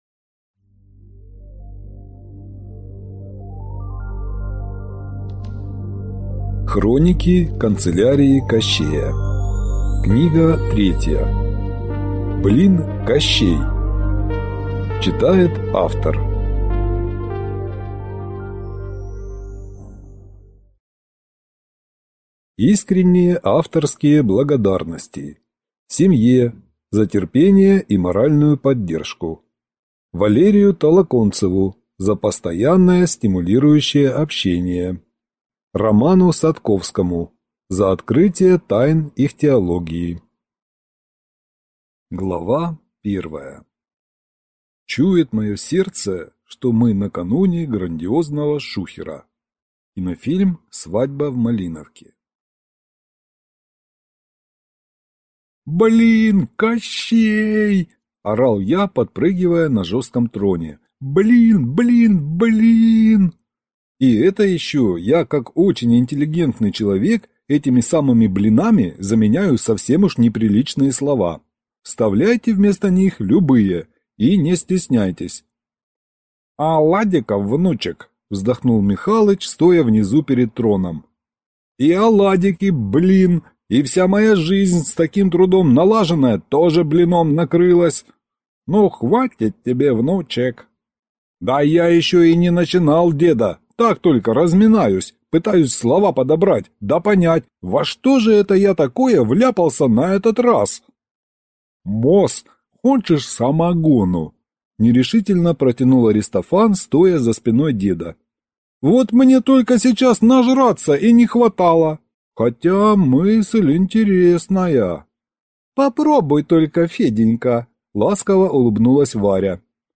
Аудиокнига Блин, Кощей!
Прослушать и бесплатно скачать фрагмент аудиокниги